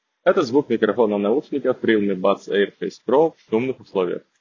Шумная обстановка — запись на улице возле 6-ти полосной дороги с максимальным шумом автомобилей.